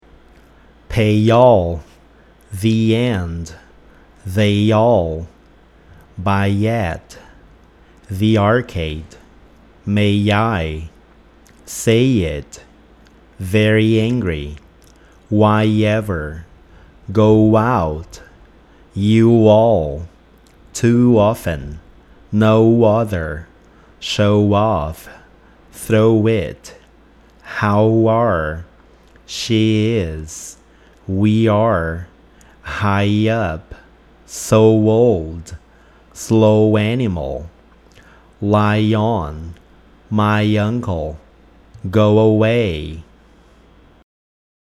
Nesse caso, a sensação que fica é que estamos colocando um som de i e u a mais para se juntar com o som vocálico da palavra seguinte.